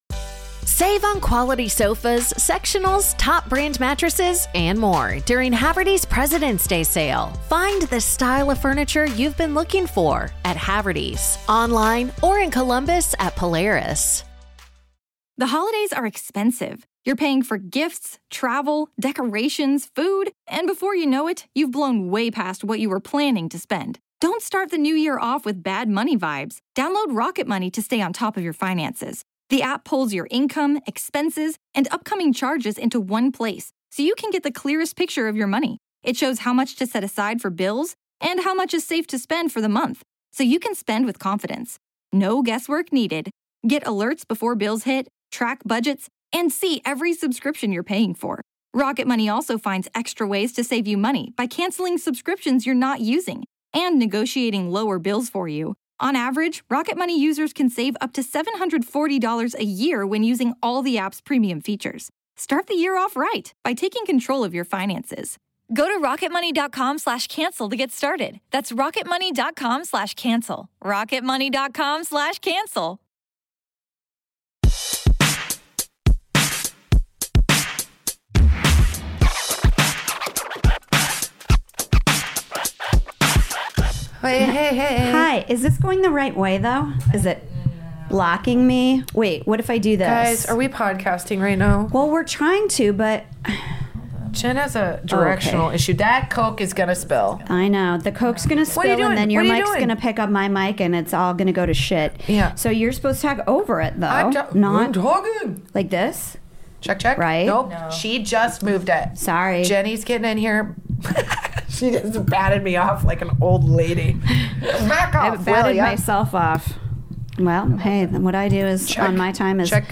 This hilarious comedy podcast about motherhood is for moms by moms talking all about being a mom.